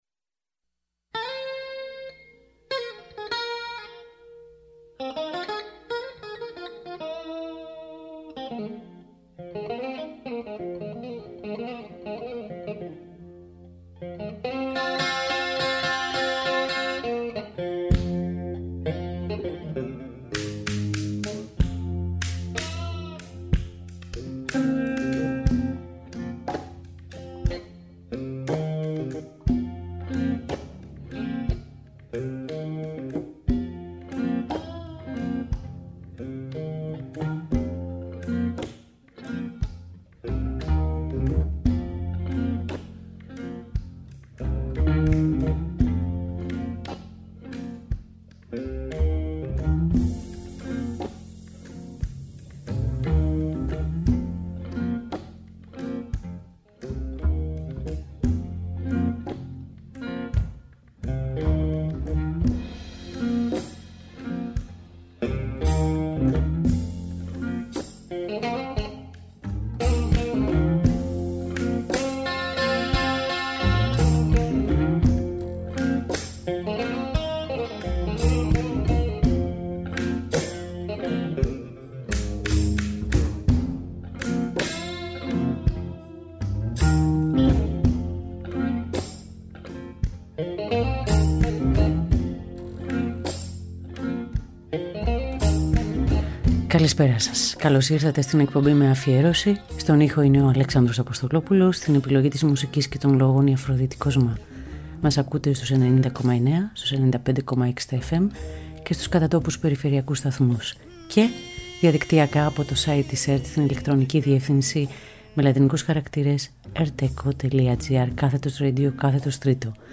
fusion και acid jazz μουσικής
cosmic free jazz
progressive rock
Ζωντανά από το στούντιο